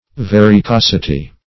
Varicosity \Var`i*cos"i*ty\, n.